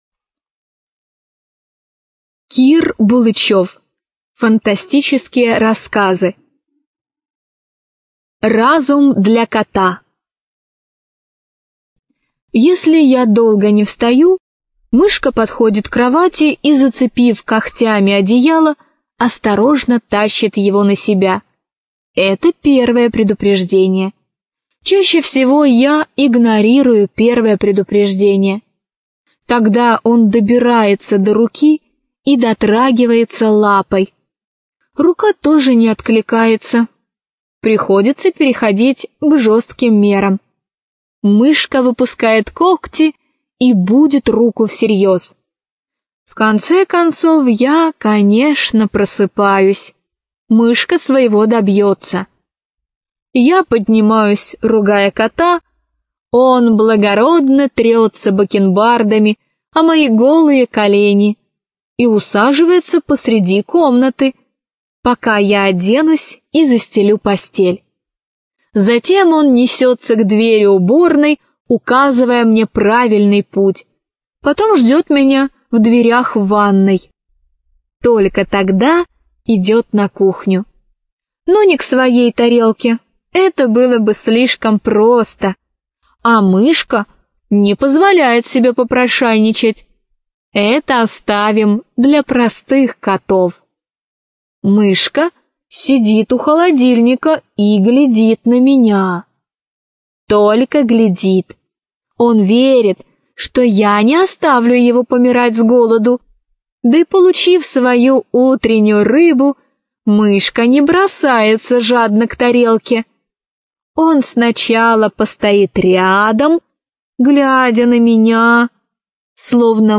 Информация о книге Фантастические рассказы (Библиотека ЛОГОС)